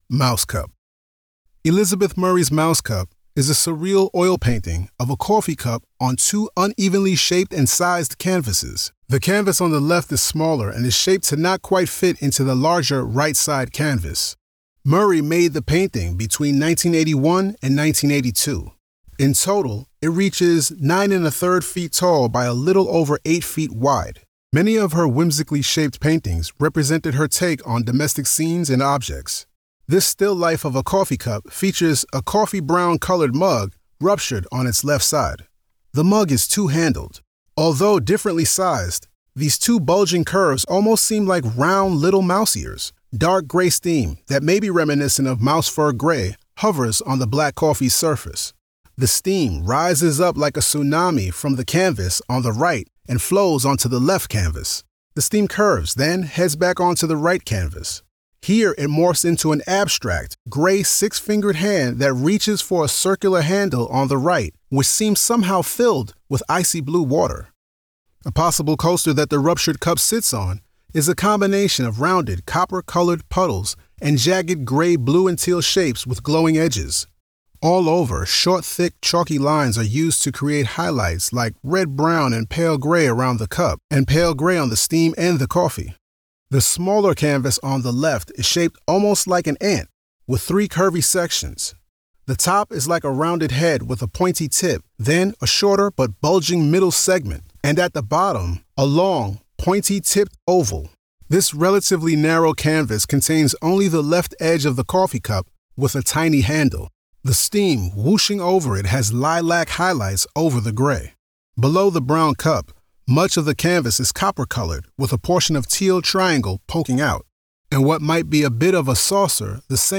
Audio Description (02:54)